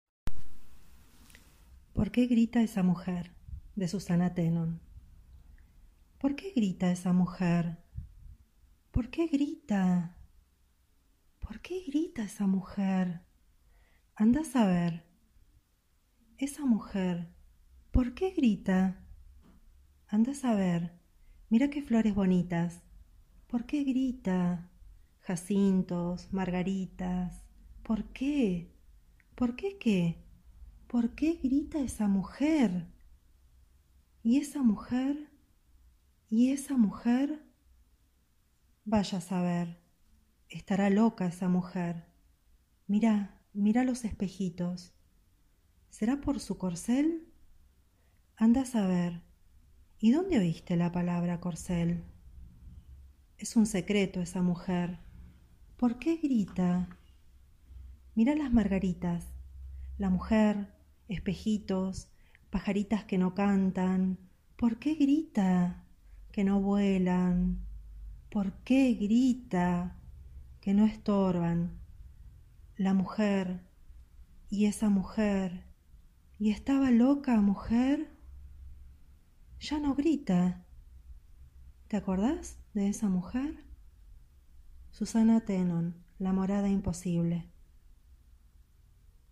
te cuento. hoy nos lee «Por qué grita esa mujer» de Susana Thénon*.